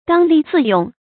剛戾自用 注音： ㄍㄤ ㄌㄧˋ ㄗㄧˋ ㄩㄥˋ 讀音讀法： 意思解釋： 見「剛愎自用」。